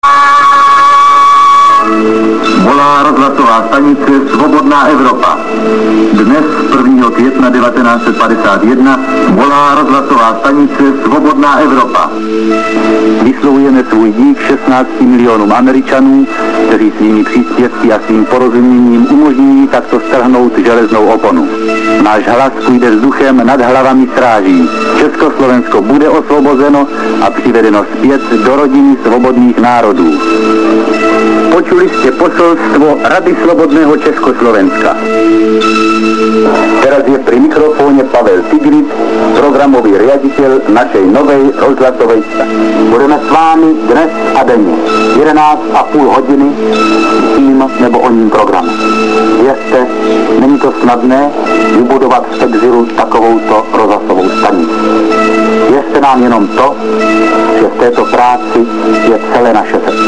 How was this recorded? Radio Free Europe started broadcasting on July 4, 1950. The first broadcast was to Czechoslovakia.